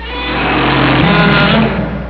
Hear the Dinosaurs, Click HERE
dinosaur.wav